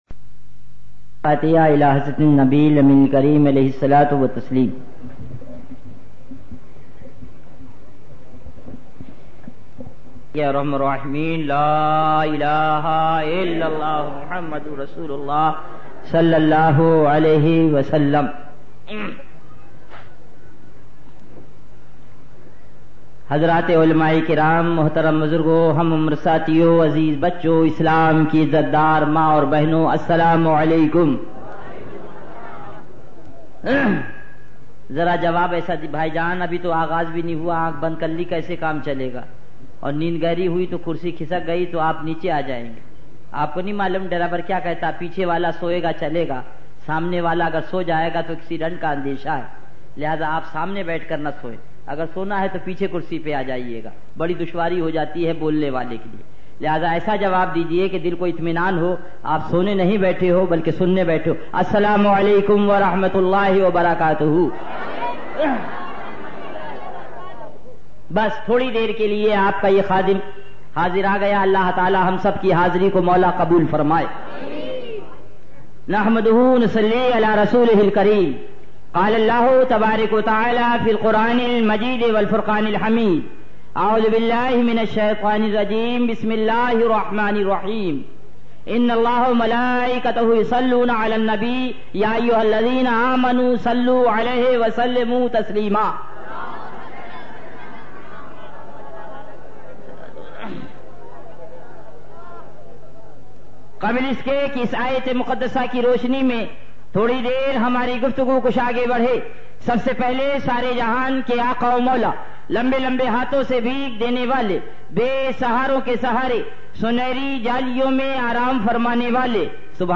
Category : Speeches | Language : Urdu